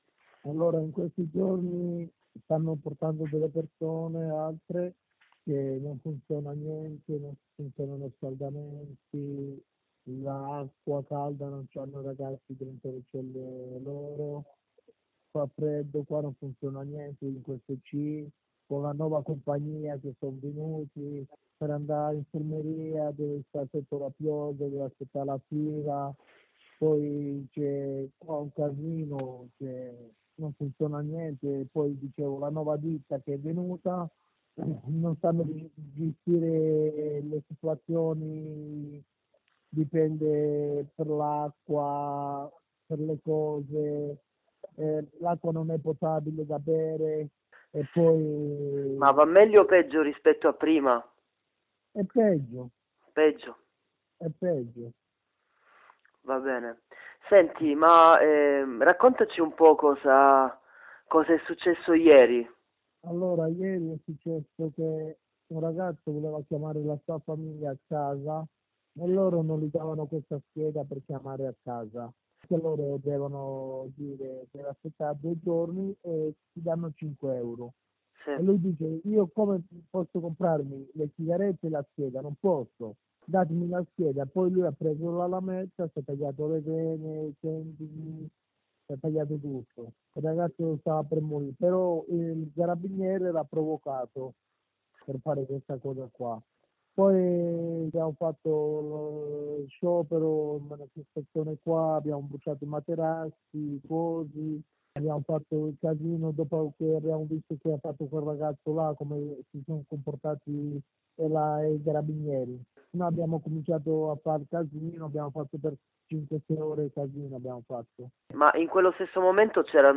Ascolta dalla viva voce di un recluso il racconto del pomeriggio di protesta, o